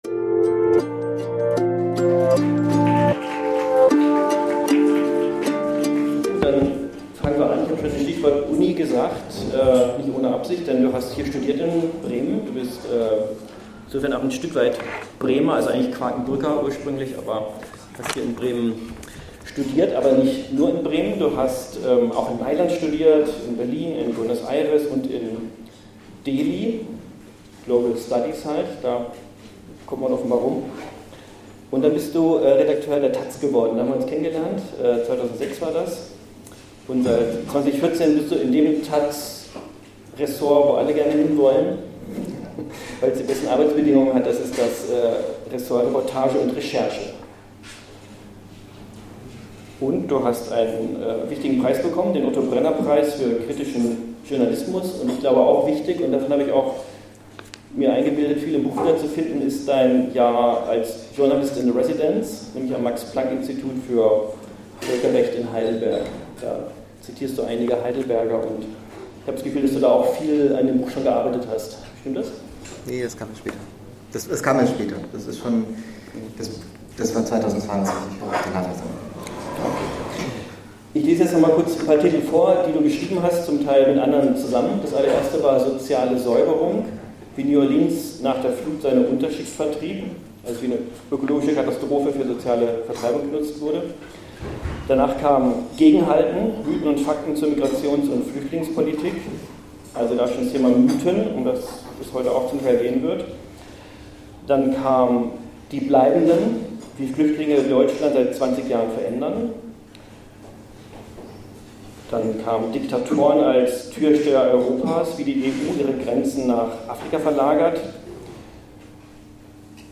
Lesung und Gespräch